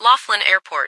- New ATIS Sound files created with Google TTS en-US-Studio-O